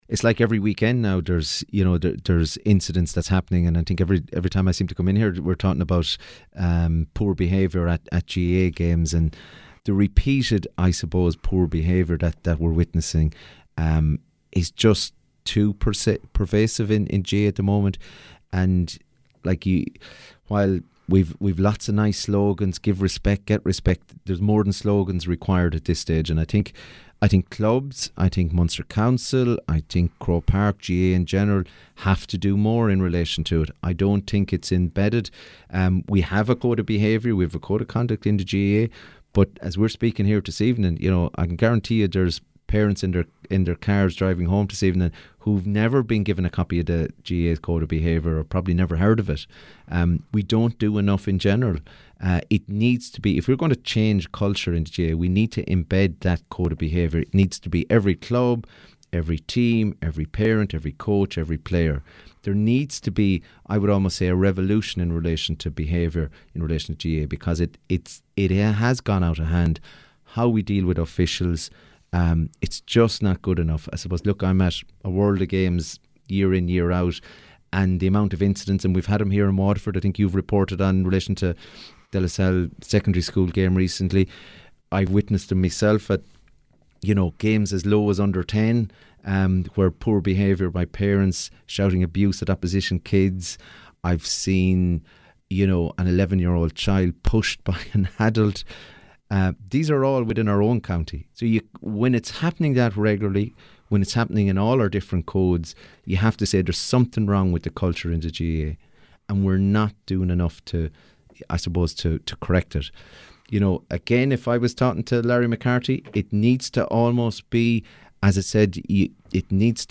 He spoke strongly about the subject on WLR’s Lár Na Páirce show after a spate of ugly incidents over recent weeks.